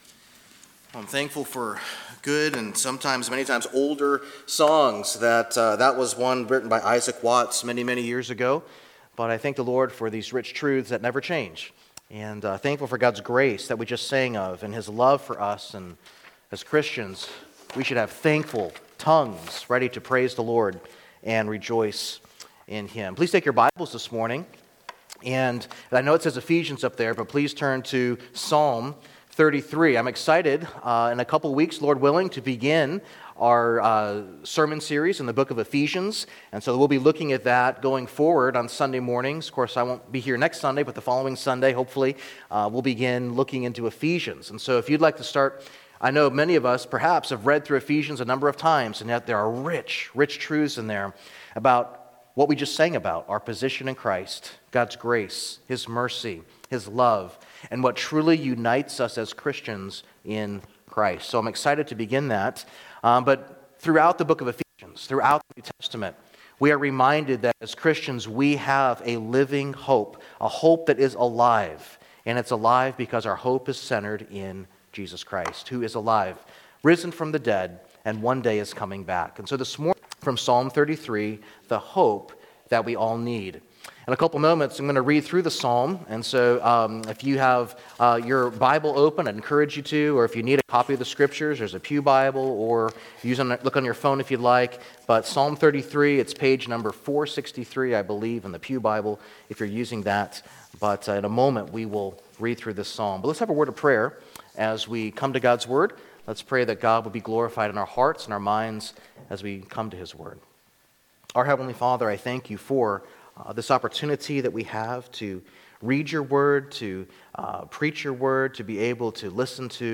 Sermons | Open Door Bible Church